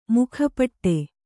♪ mukha paṭṭe